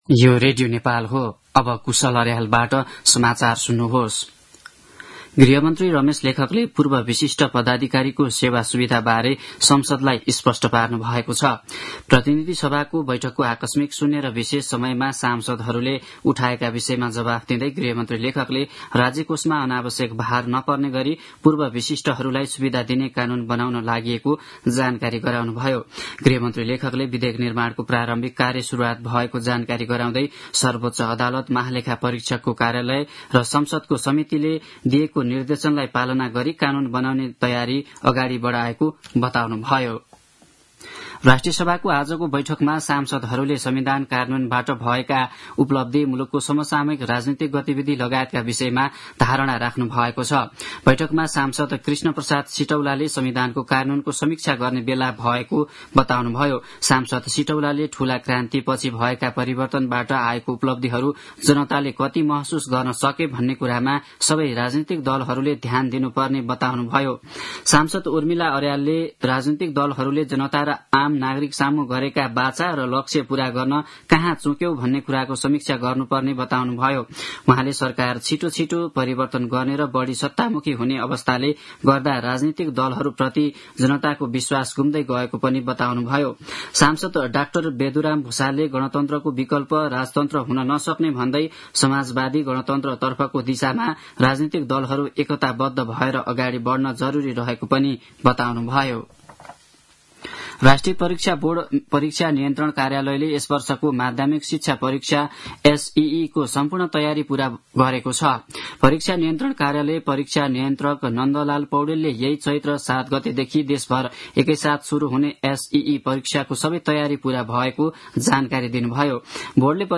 दिउँसो ४ बजेको नेपाली समाचार : ४ चैत , २०८१
4-pm-news-2.mp3